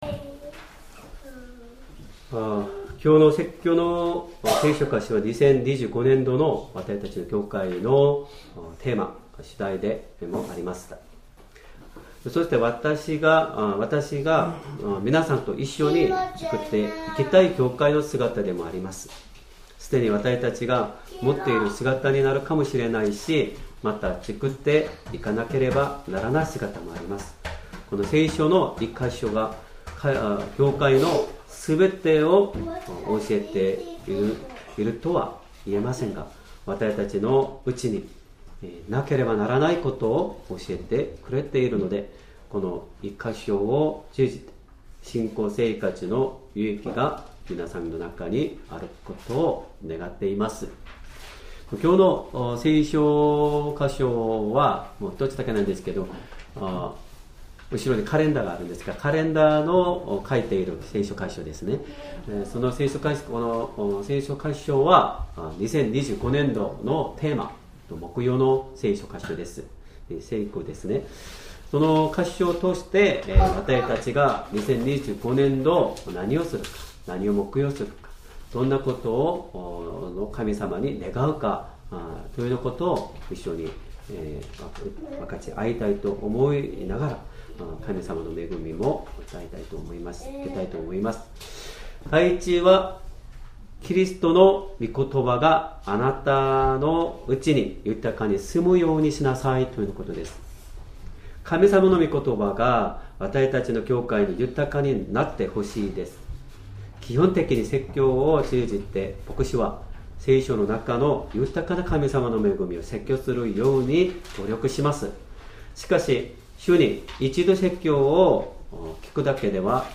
Sermon
2025年5月4日 主日礼拝 説教 「主の御言葉があなたがたのうちに住むように 」 聖書 コロサイ人への手紙3章16節 3:16 キリストのことばが、あなたがたのうちに豊かに住むようにしなさい。